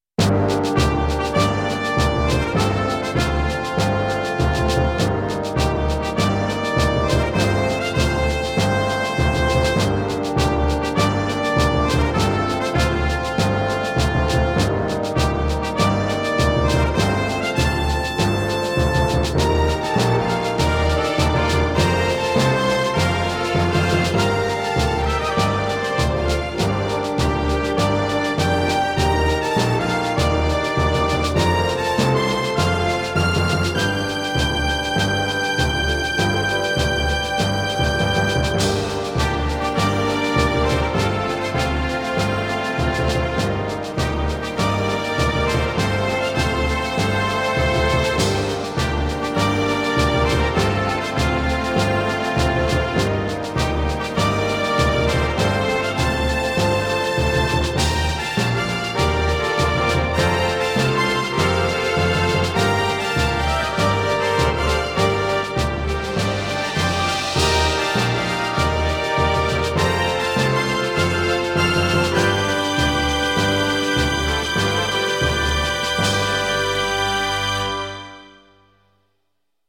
エレクトーンでゲーム音楽を弾きちらすコーナー。
使用機種：ELS-01C